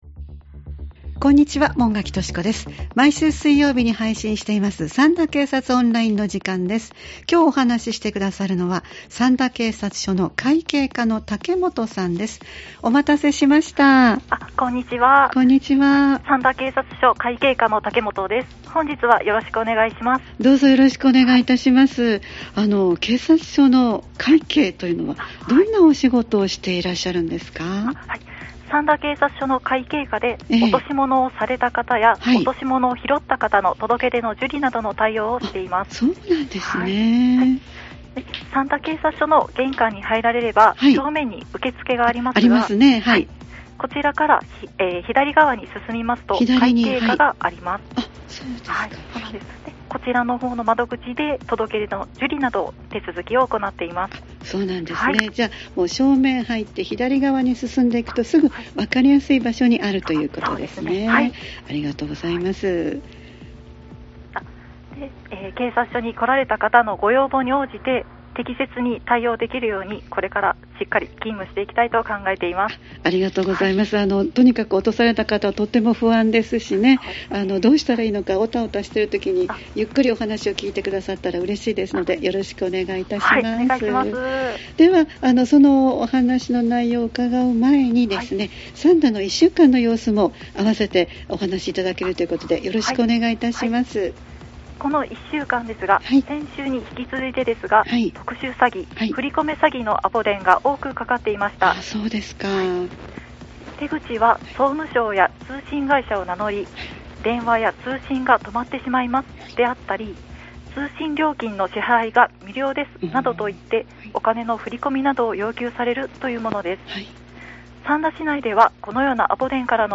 三田警察署に電話を繋ぎ、一週間の事件事故、防犯情報、警察からのお知らせなどをお聞きしています（再生ボタン▶を押すと番組が始まります）